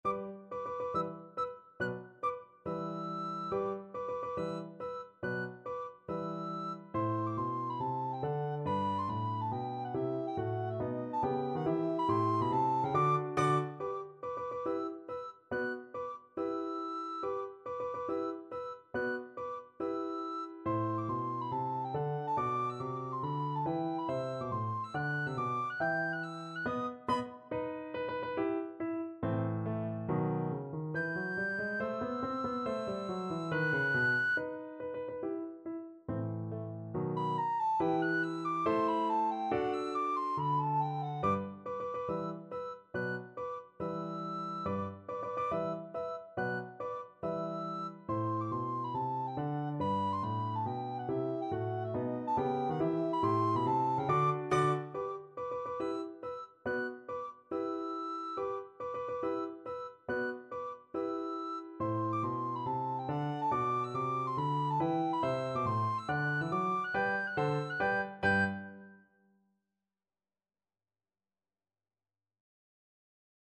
Free Sheet music for Soprano (Descant) Recorder
4/4 (View more 4/4 Music)
G major (Sounding Pitch) (View more G major Music for Recorder )
Tempo di marcia =140
Classical (View more Classical Recorder Music)